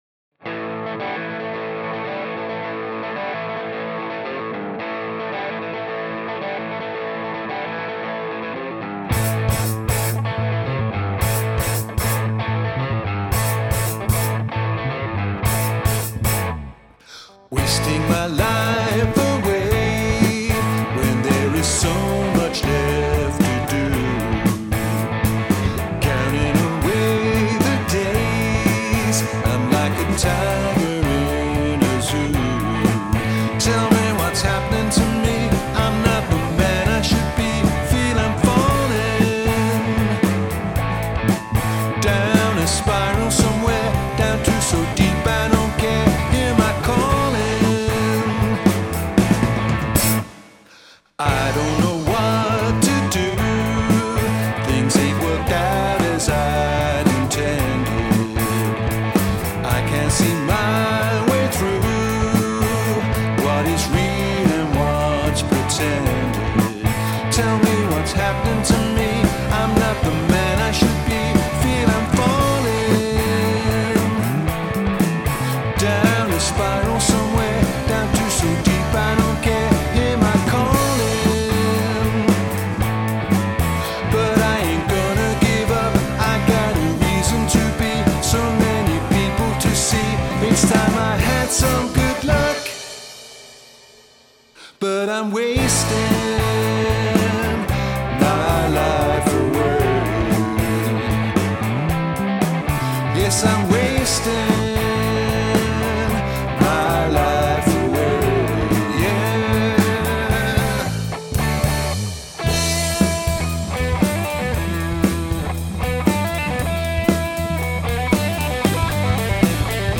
Nous jouons essentiellement du rock, funk et reggae.